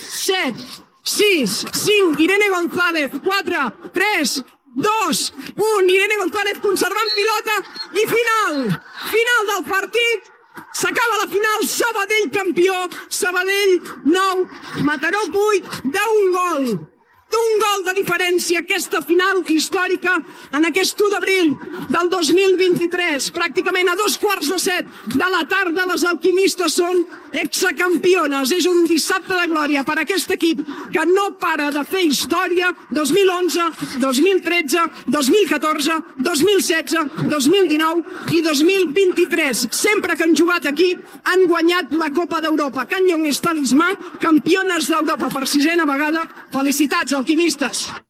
Últims segons de la transmissió de la final de la Copa d'Europa de Waterpolo entre el Sabadell i el Mataró. El Sabadell femení guanya la seva sisena "Champions" de waterpolo, després de superar el Mataró, 8 a 9
Esportiu